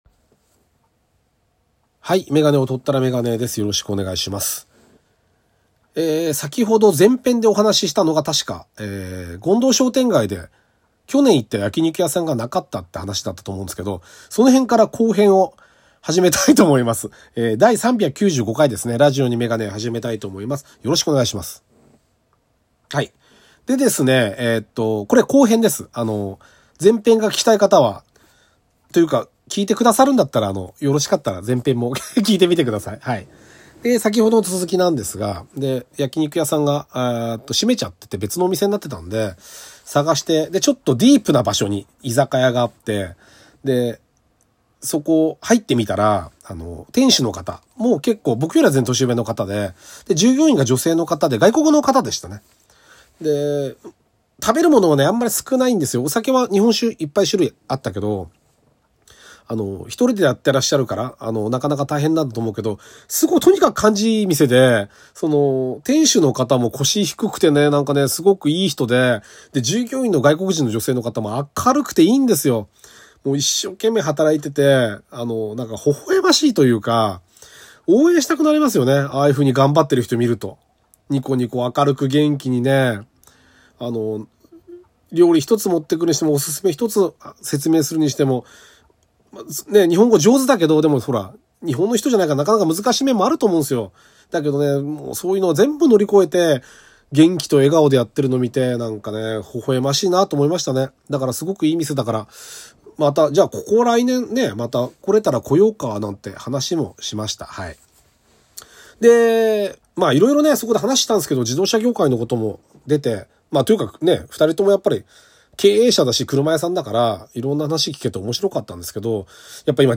ほぼ中身の無い雑談配信となりますがよろしくお願いします。